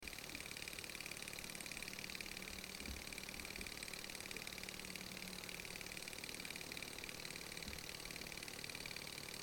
マンソンエンジン マンソンエンジンは内燃機関のような燃焼（爆発）工程が無い外燃機関ですので音が静かで排気ガスもない、室内で楽しめるミニチュアエンジンです。
心地よいブルブル音（mp3）を出します。